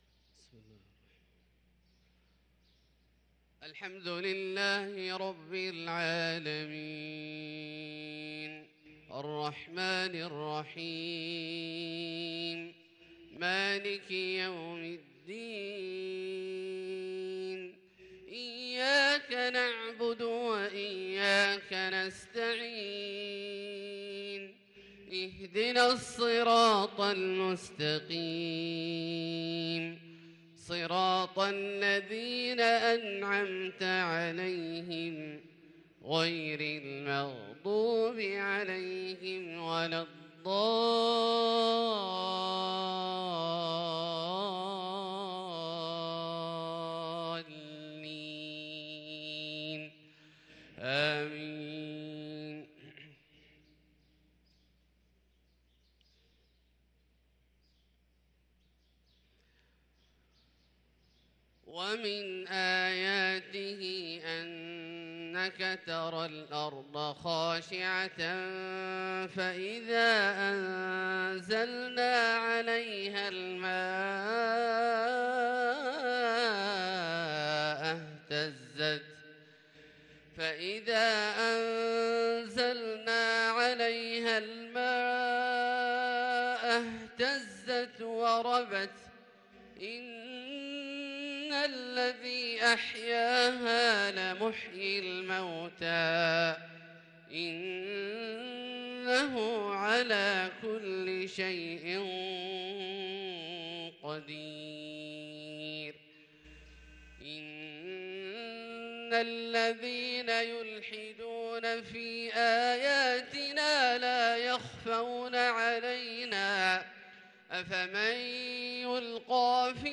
صلاة الفجر للقارئ عبدالله الجهني 24 ربيع الأول 1444 هـ